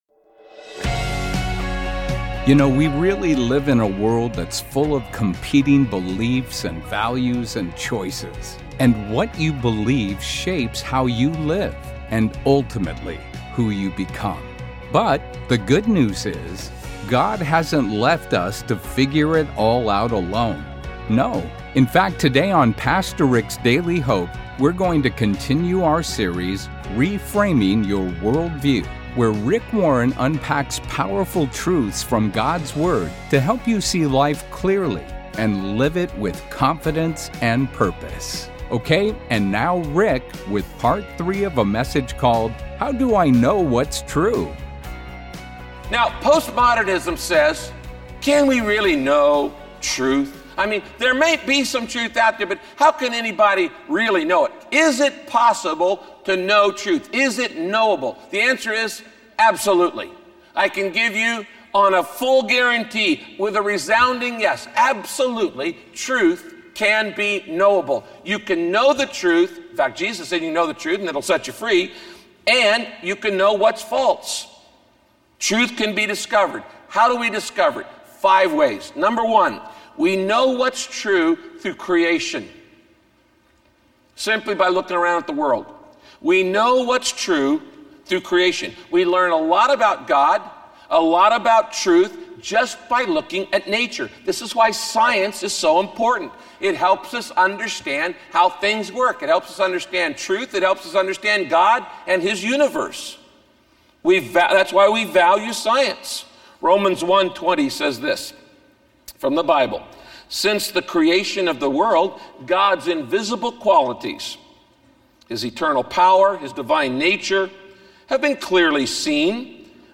In this message, Pastor Rick continues to teach about how God shows us tr…